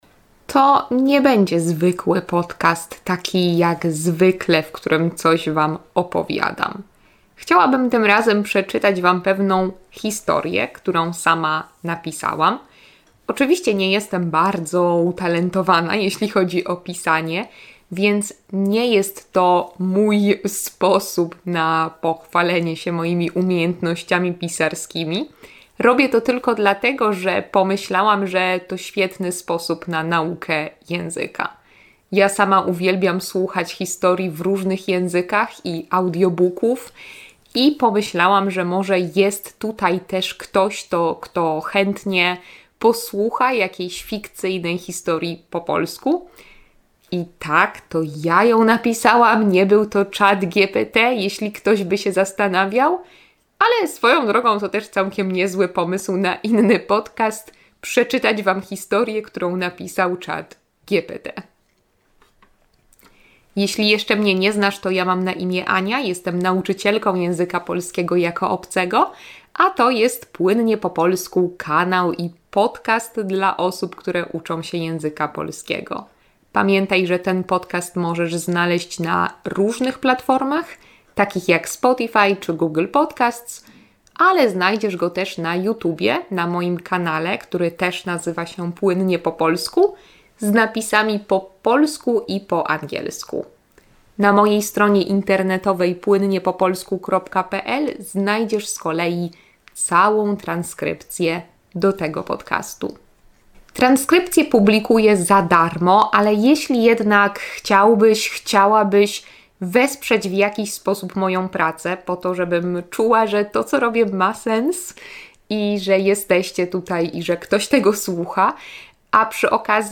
Chciałabym tym razem przeczytać wam pewną historię, którą napisałam.